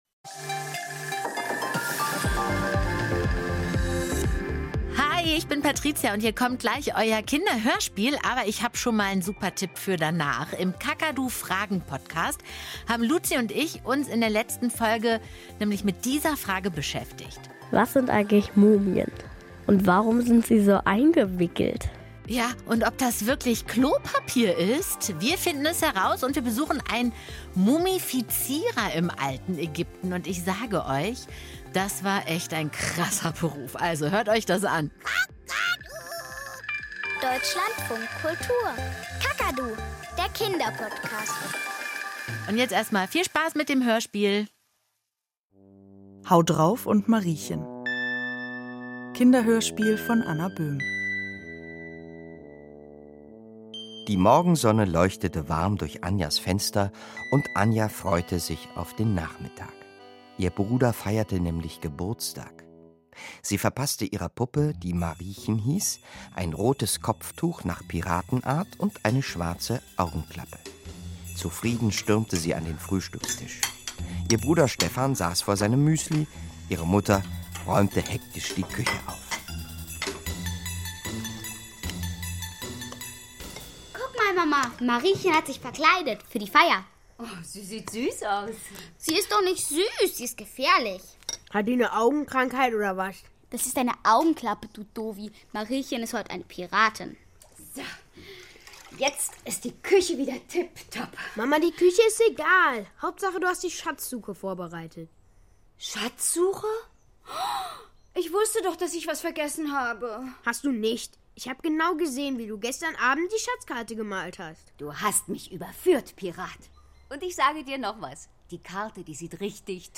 Kinderhörspiel - Haudrauf und Mariechen